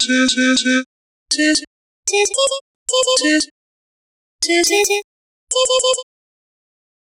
Tsut voice
emulator_tsut.mp3